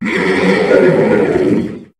Cri de Tokotoro dans Pokémon HOME.